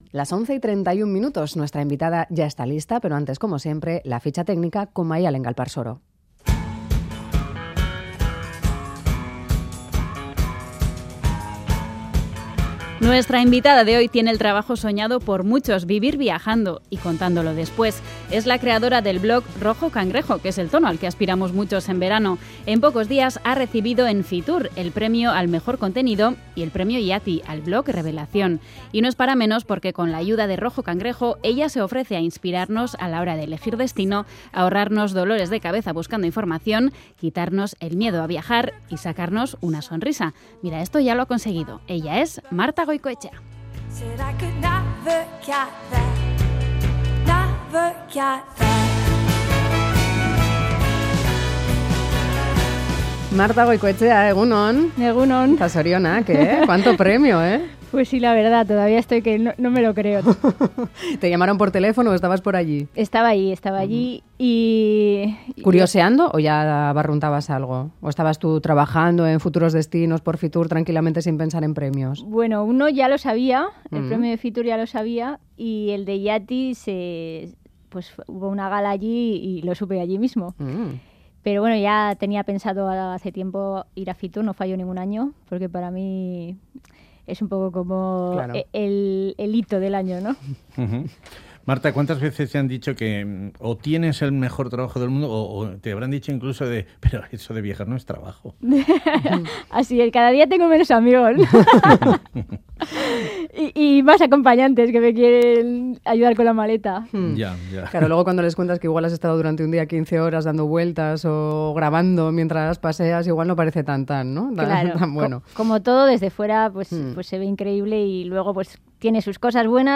Hablamos con ella sobre su pasión, viajar.